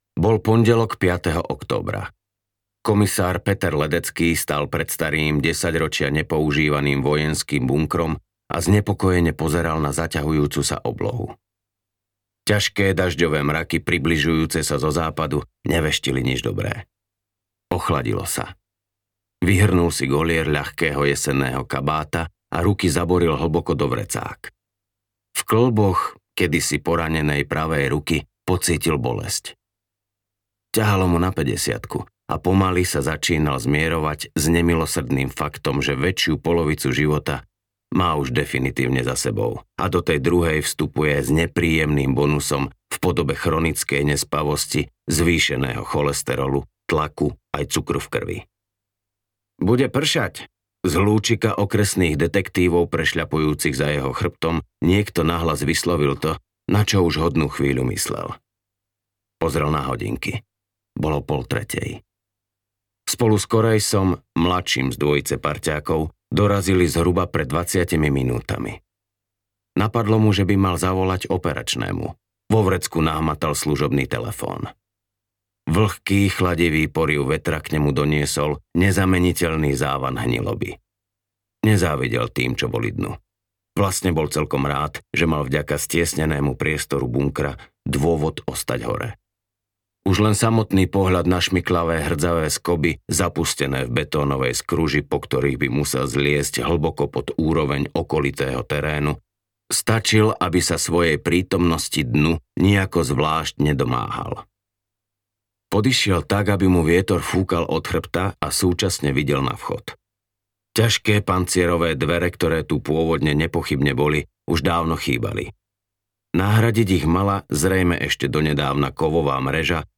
Uplakaná jeseň audiokniha
Ukázka z knihy
uplakana-jesen-audiokniha